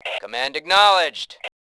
Male3
cmd.acknowledge.WAV